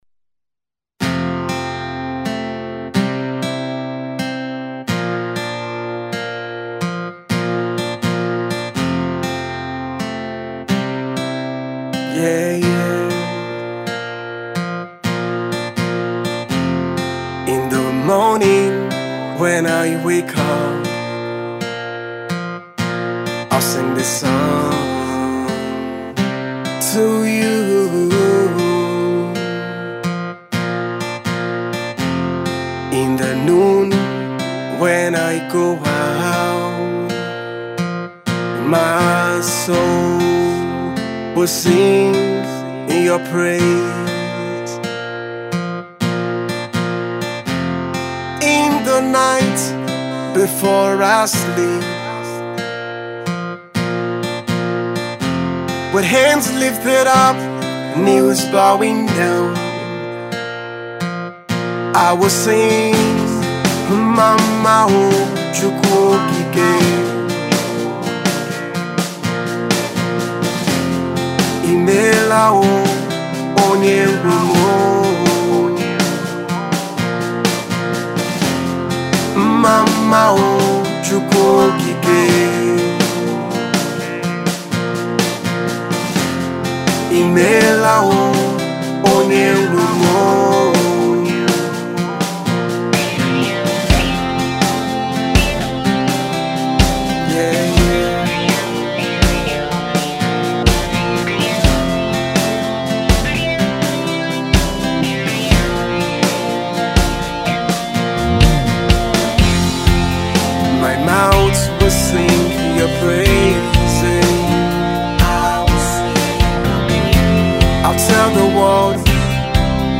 soft rock worship